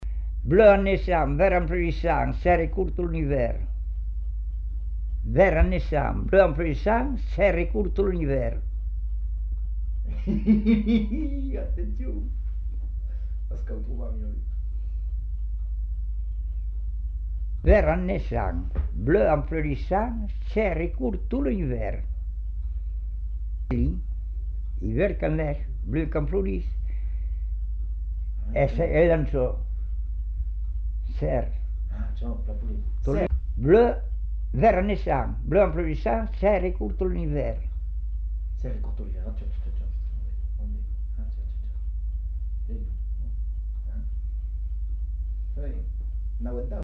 Aire culturelle : Savès
Genre : forme brève
Effectif : 1
Type de voix : voix d'homme
Production du son : récité
Classification : devinette-énigme